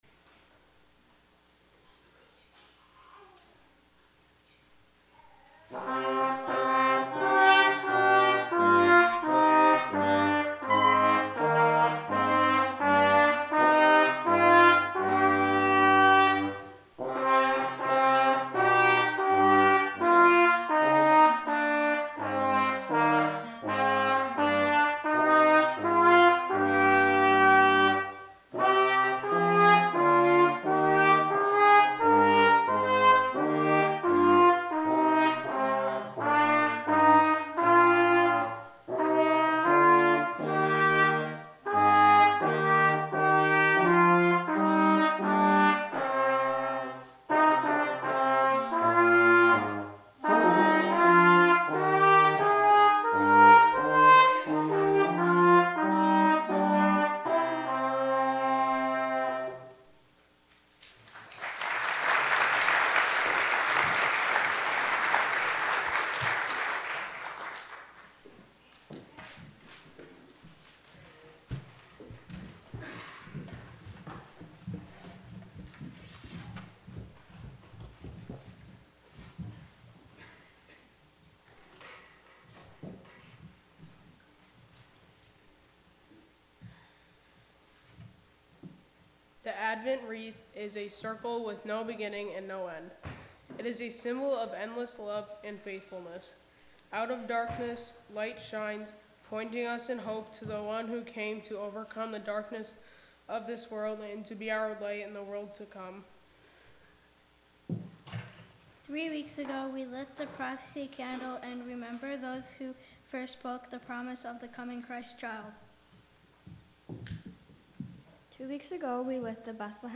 Sunday School Christmas Program